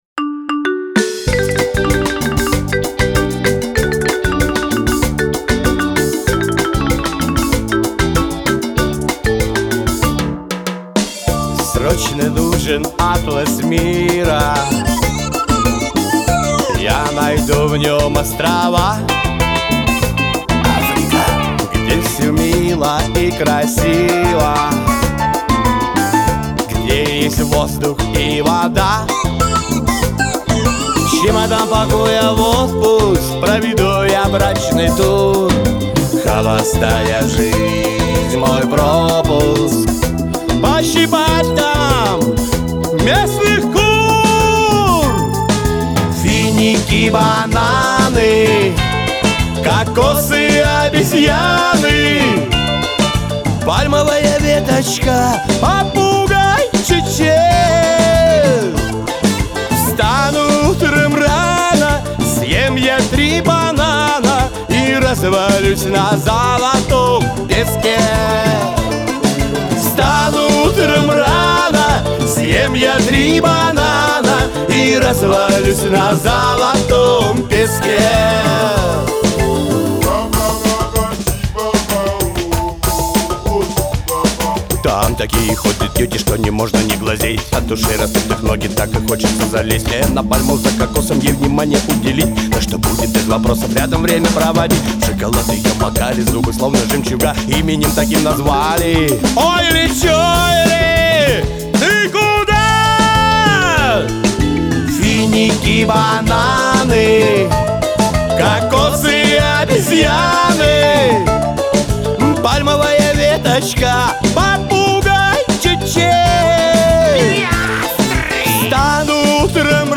Жанр: Шансон